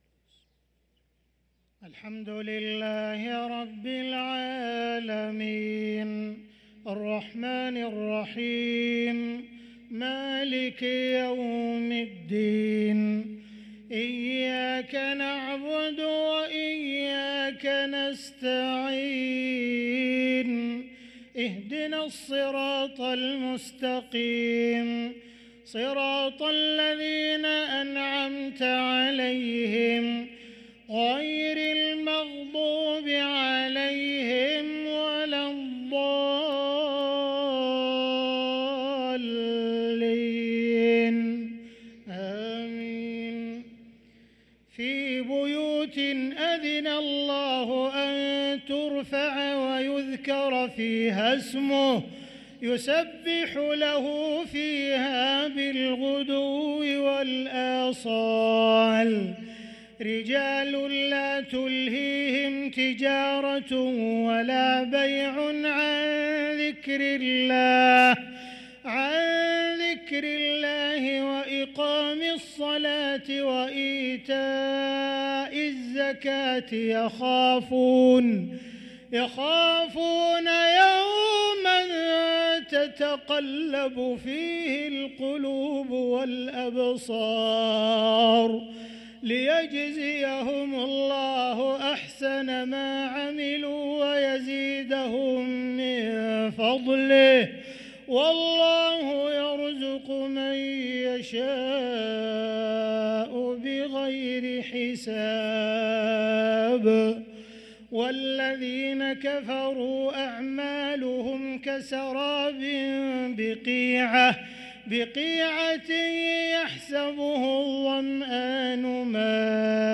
صلاة العشاء للقارئ عبدالمحسن القاسم 19 جمادي الأول 1445 هـ
تِلَاوَات الْحَرَمَيْن .